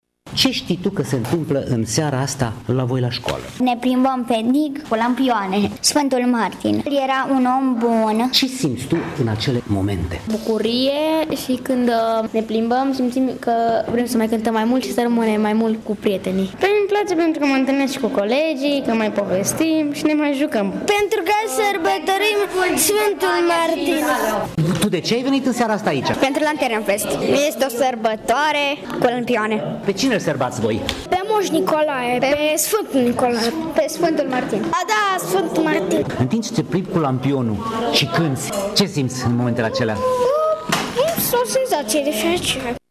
În această seară de la ora 18.00, din Gimnaziul Friedrich Schiller din Tîrgu-Mureș au ieșit mai bine de 100 de copii purtând în mâini lampioane și cântând cântece în limba germană.